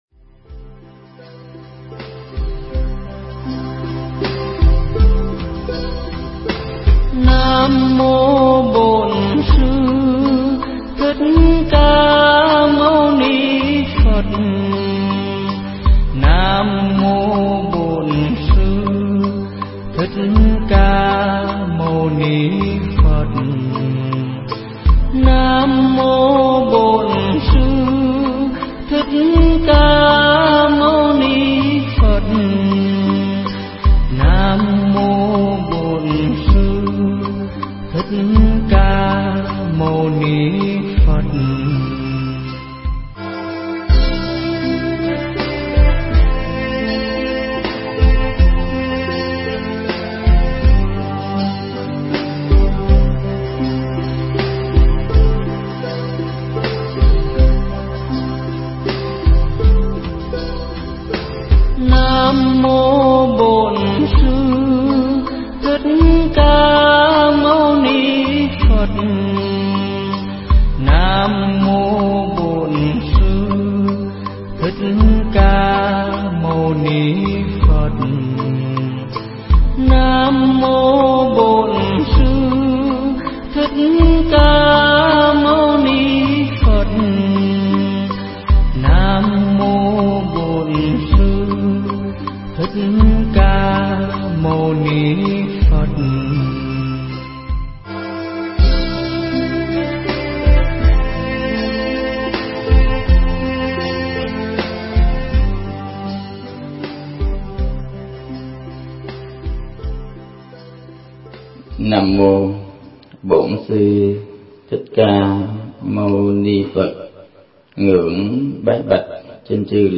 Mp3 Thuyết Pháp Chánh kiến niệm Phật